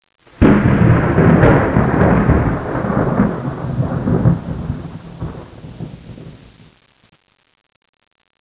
thunder_clap.wav